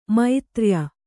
♪ maitrya